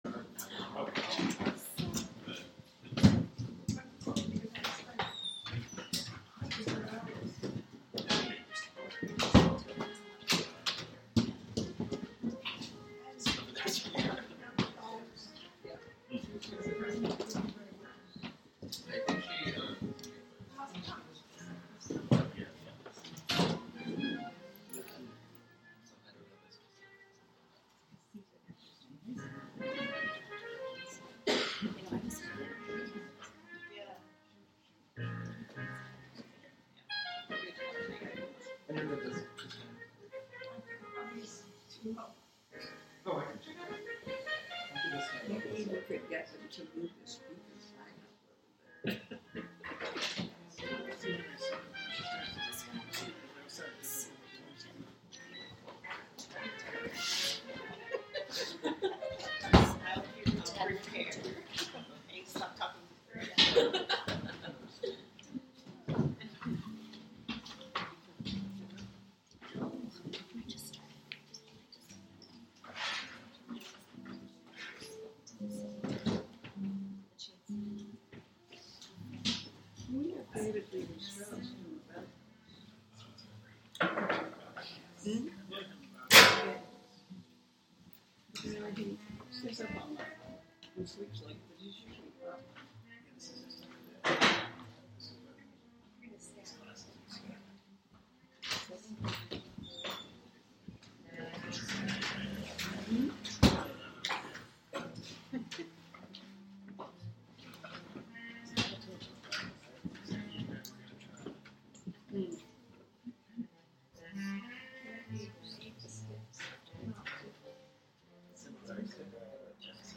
Live from The Flow Chart Foundation
Ann Lauterbach reading and in conversation